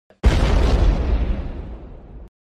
Voicy_explosion-sound-effect.mp3